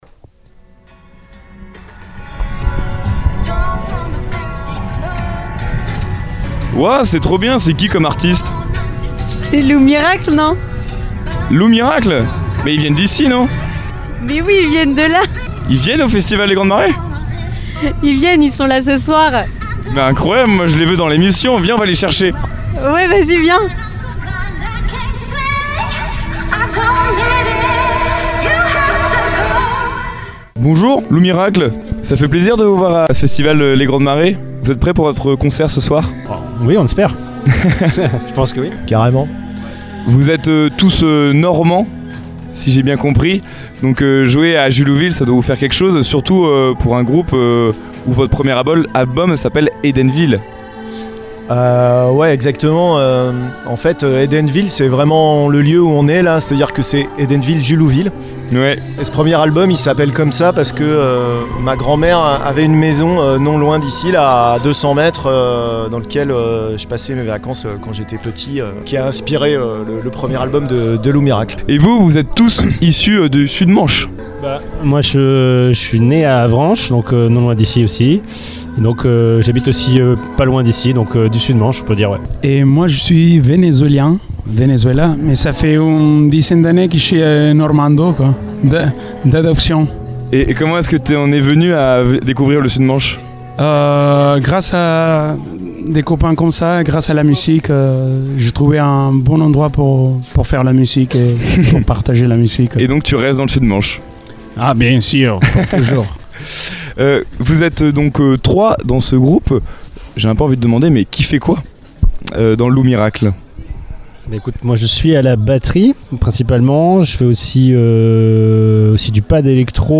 Dans cet épisode du Mystery Machine Summer Tour, nous partons à la rencontre du groupe Lou Miracle, programmé dans le cadre du festival Les Grandes Marées.